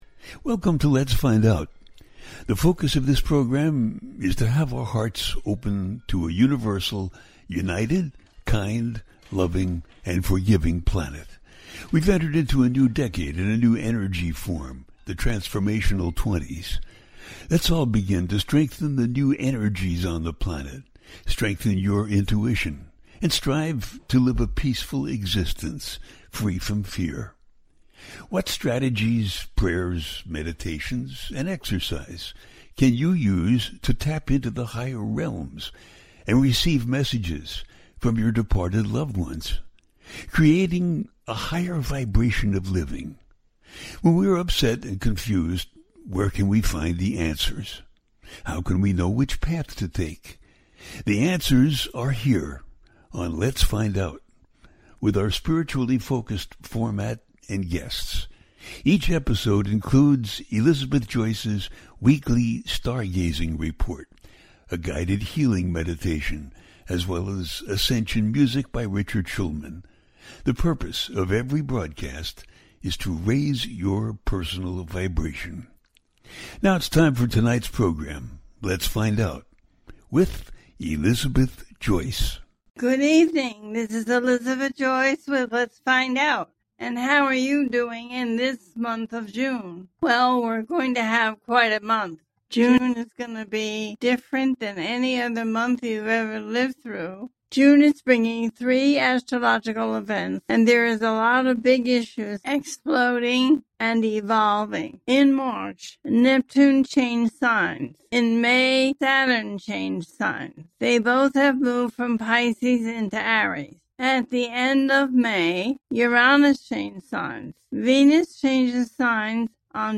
June Brings Big Issues Expanding And Evolving - A teaching show